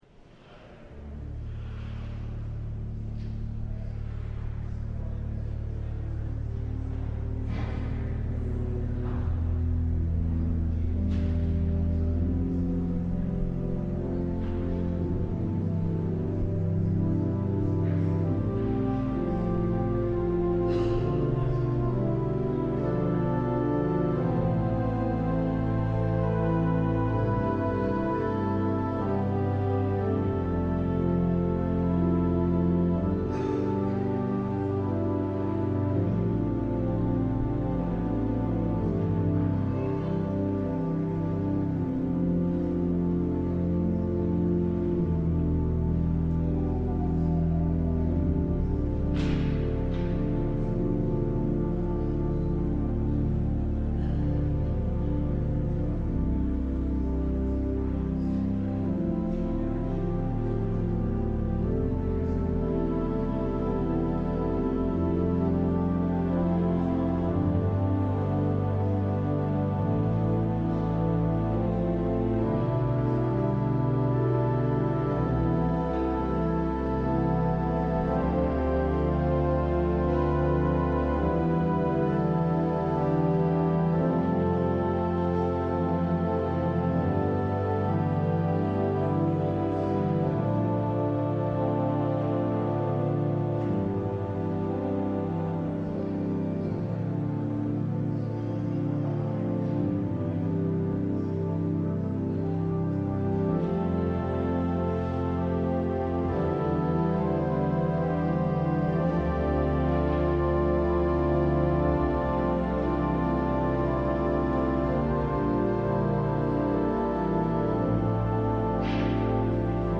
LIVE Morning Service - Cross Words: Taking Up the Cross